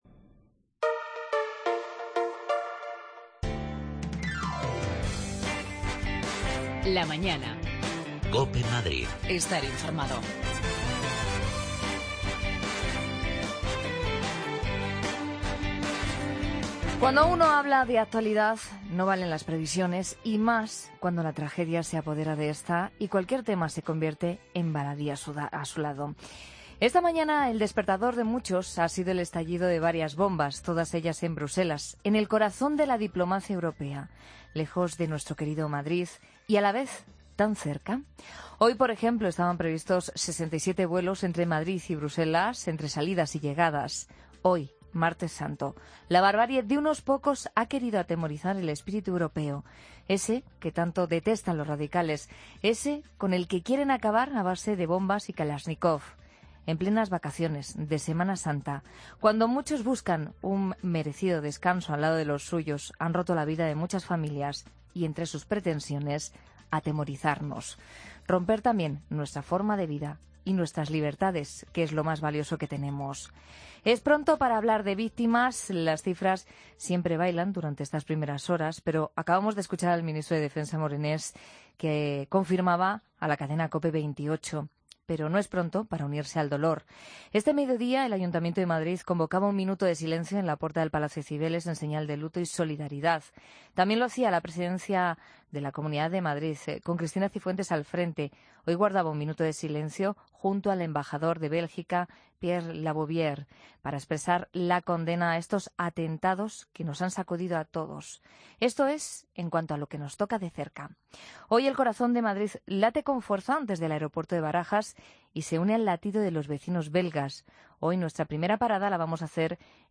Hoy entrevistamos a Esteban González Pons, portavoz de la Delegación Española en Bruselas, y vicepresidente del Grupo Popular en el Parlamento Europeo. El segundo atentado ha sido a dos manzanas del Parlamento. Hoy también nos desplazamos hasta el aeropuerto Adolfo-Suárez Barajas para conocer cómo están viviendo estas primeras horas los viajeros, algunos de ellos, con destino hoy a la capital del dolor.